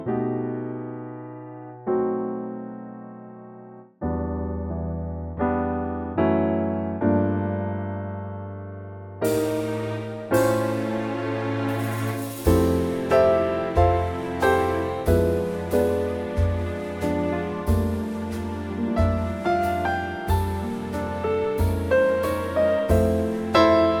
key D (instrumental section in F)
vocal range - B to E (big finish!)
Lovely Trio and Strings arrangement